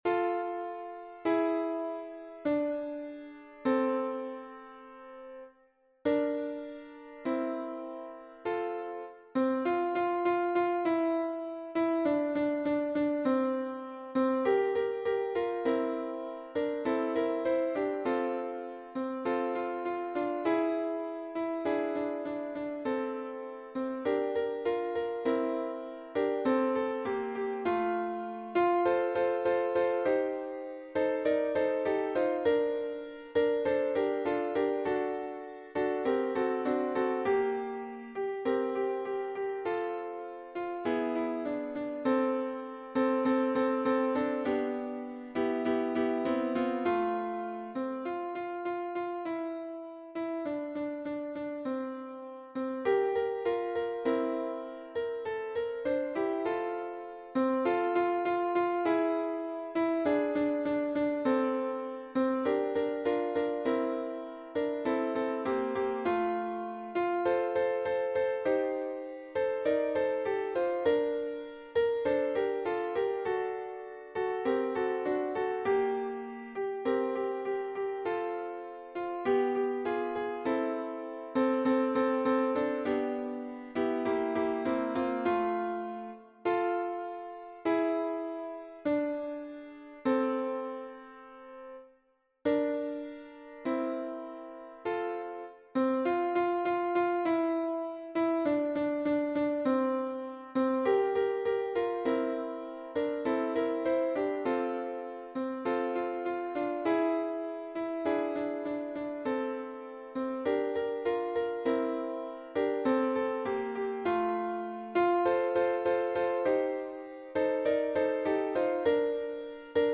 MP3 version piano
Tutti